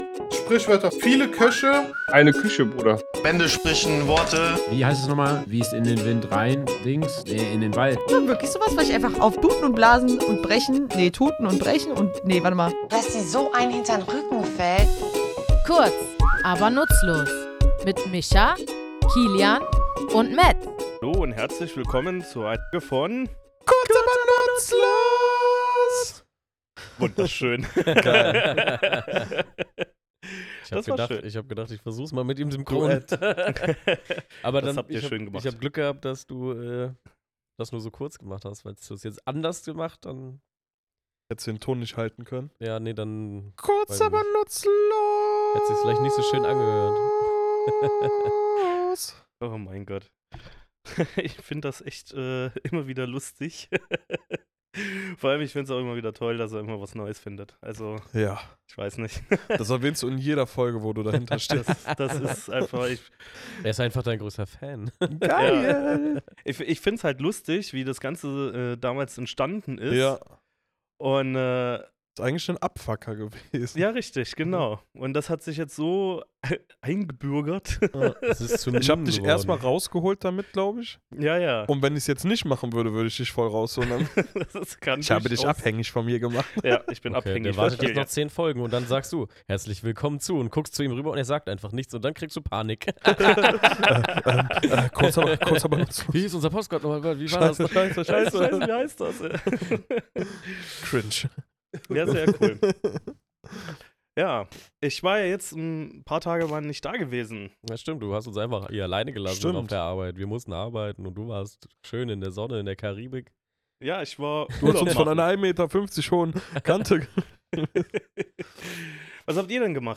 Wir, drei tätowierende Sprachnerds, graben in unserem Tattoostudio tief in der Sprachgeschichte und erklären, wie aus einem einfachen Fehler ein „Pudel“ werden konnte – und was das mit Kegeln zu tun hat.